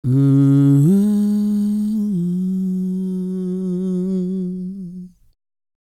E-CROON P325.wav